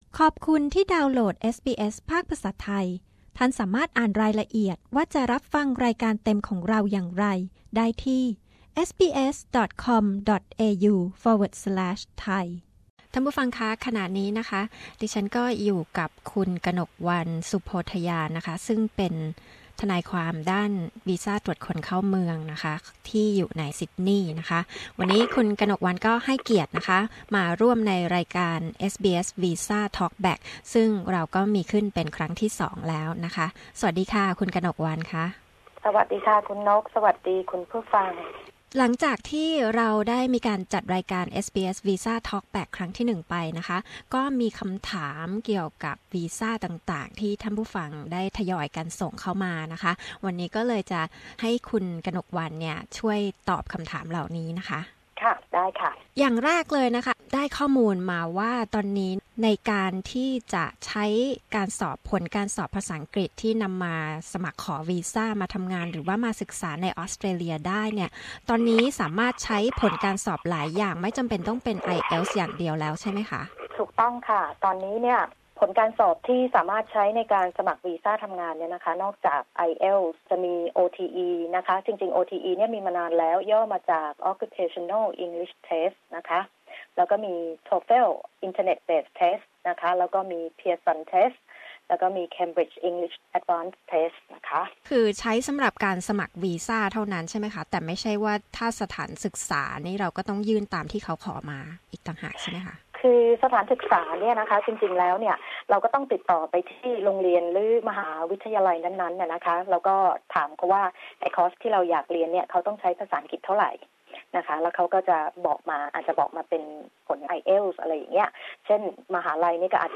SBS Thai Visa Talk Back 2